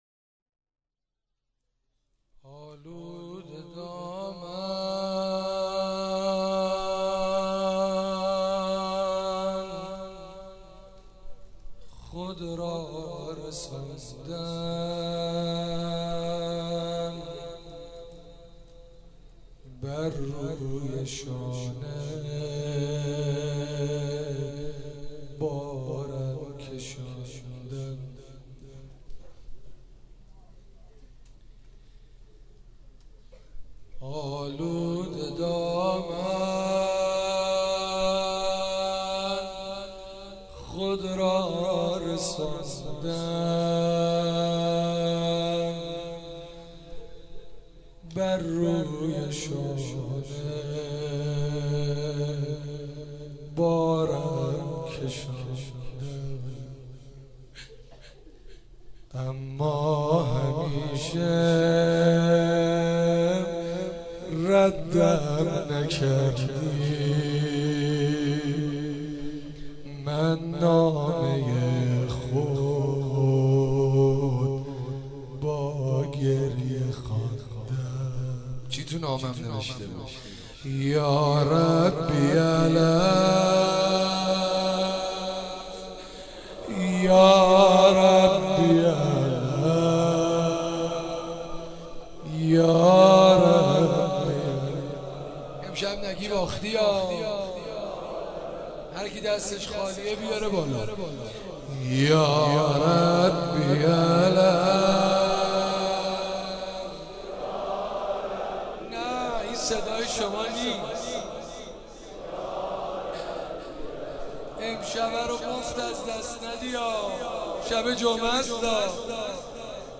مراسم شب 21 رمضان 96